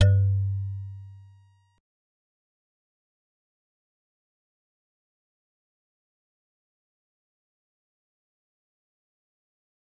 G_Musicbox-G2-pp.wav